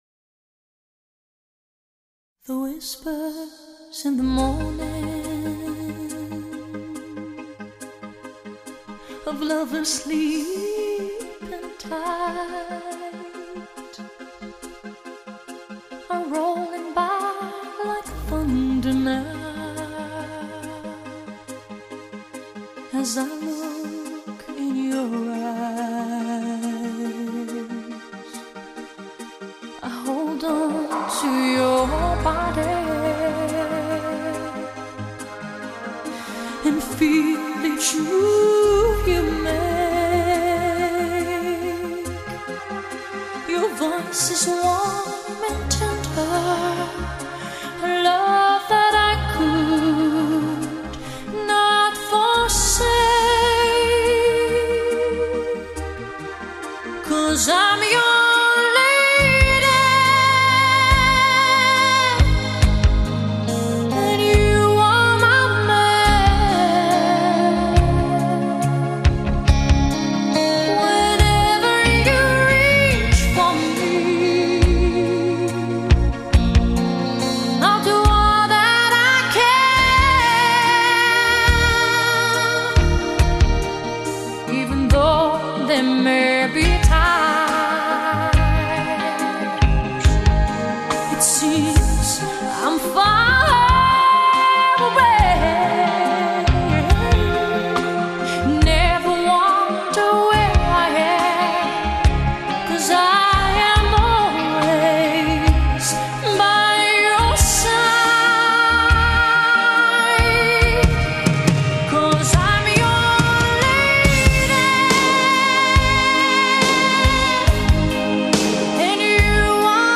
无与伦比 完美音质 堪称史上最经典的录音